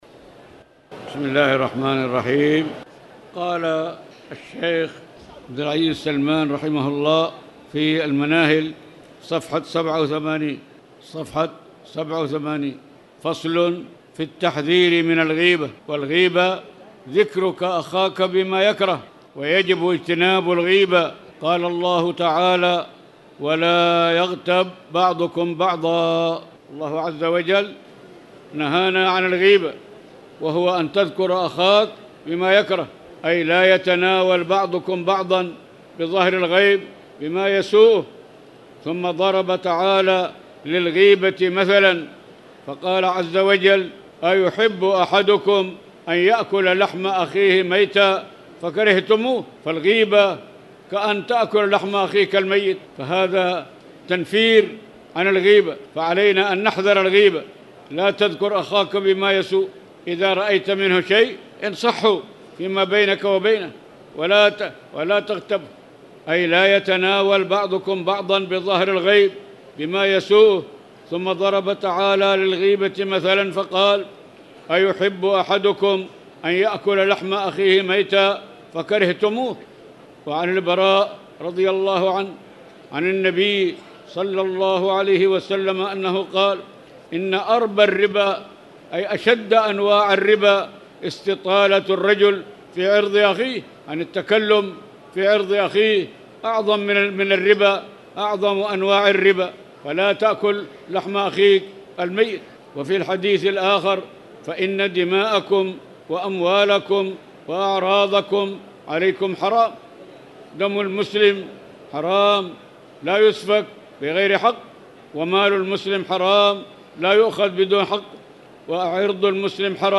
تاريخ النشر ١٦ رمضان ١٤٣٨ هـ المكان: المسجد الحرام الشيخ